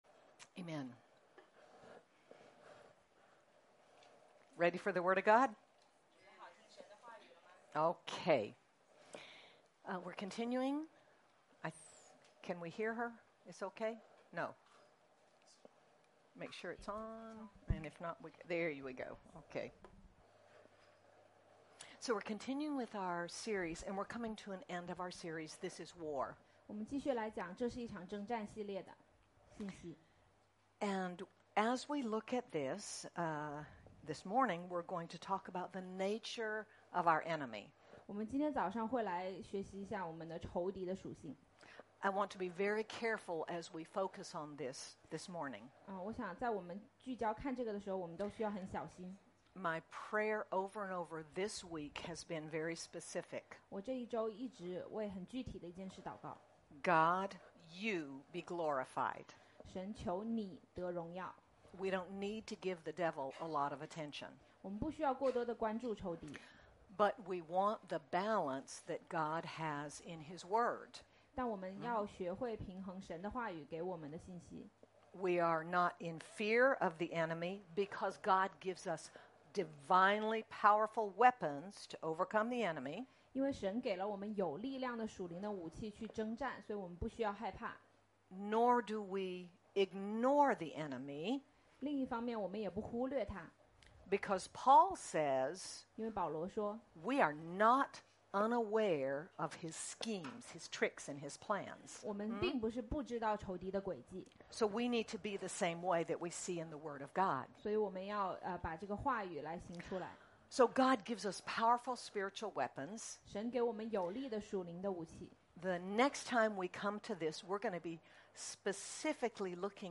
Aug 31, 2025 The Nature of Your Enemy MP3 SUBSCRIBE on iTunes(Podcast) Notes Discussion Sermons in this Series In the fight, we always keep our focus on God, but His Word is also clear: we neither ignore nor underestimate the enemy. God shows us how stand strong and be alert and wise to the enemy’s attacks and schemes. Sermon by